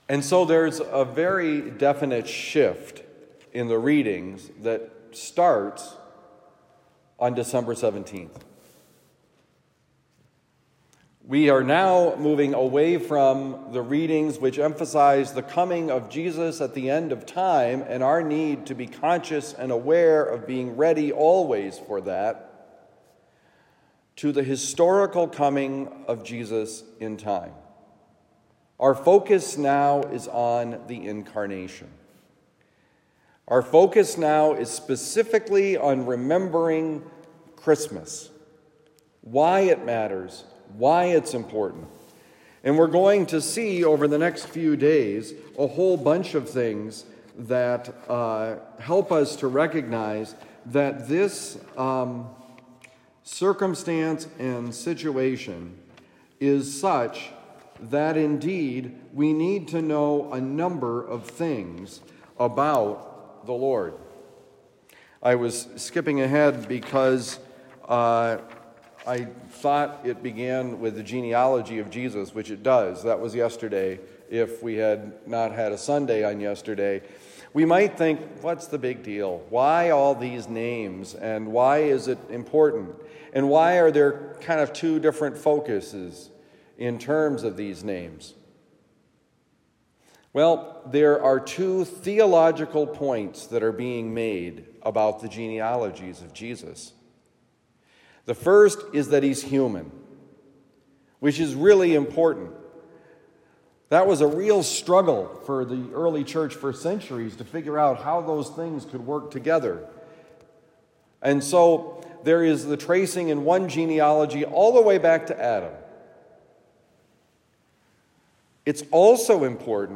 God keeps promises: Homily for Monday, December 18, 2023